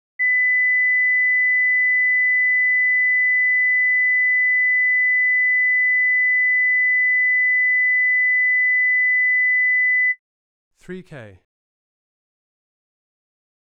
50 - 2kHz.wav